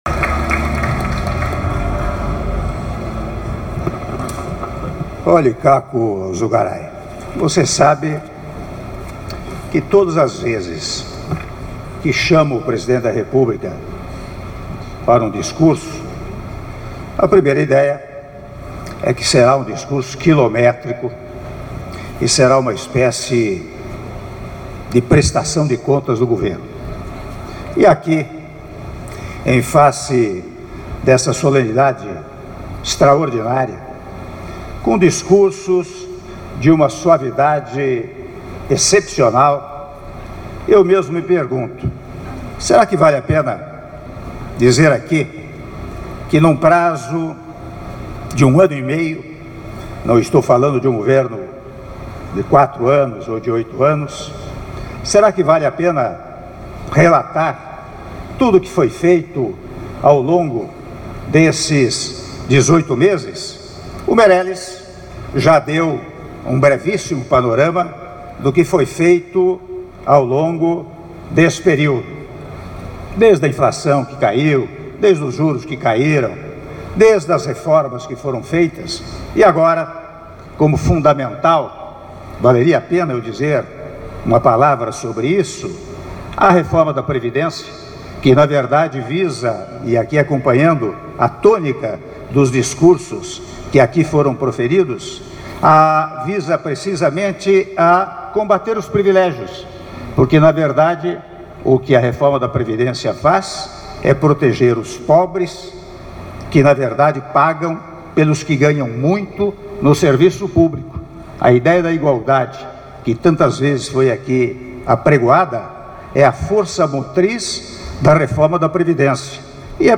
Áudio do discurso do Presidente da República, Michel Temer, durante cerimônia de entrega do título de “Brasileiro do Ano”, pela Revista Istoé, e “Empreendedor do Ano”, pela Revista Istoé Dinheiro - São Paulo (04min45s)